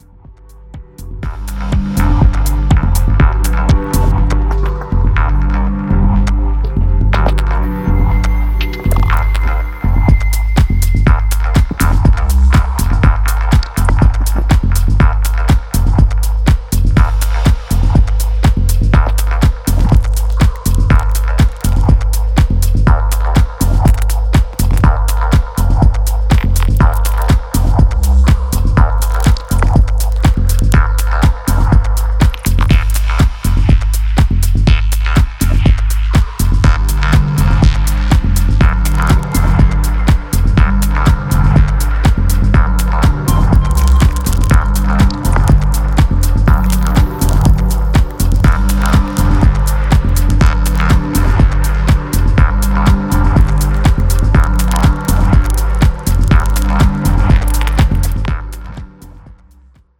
オーガニックな質感と緻密な音響設計が最高なダブ・テクノ作品。
New Release Dub Techno Techno